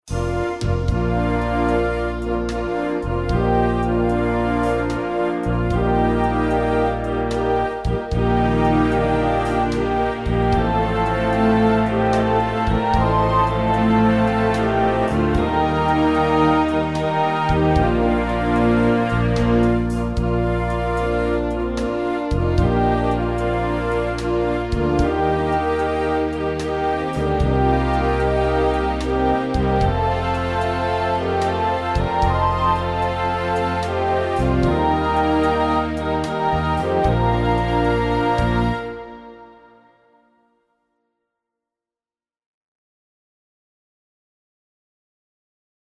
Estrofe 1